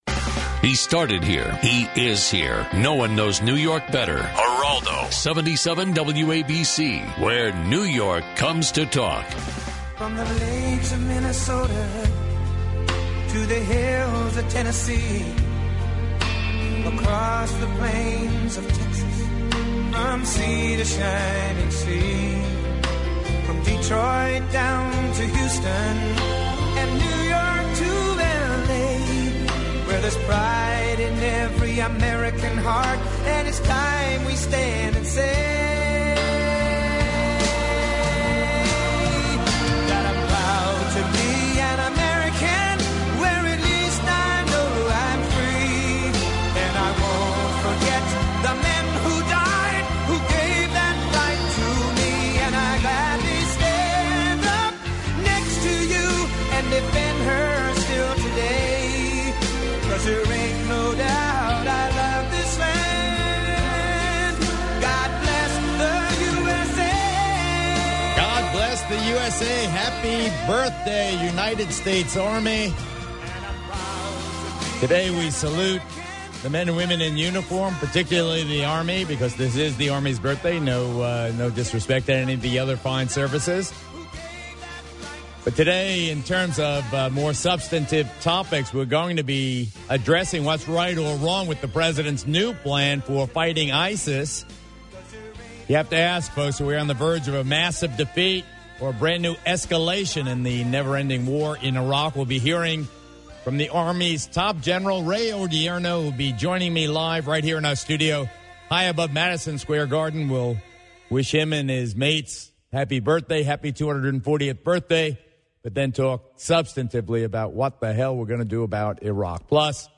Geraldo covers the latest news & headlines, live and local!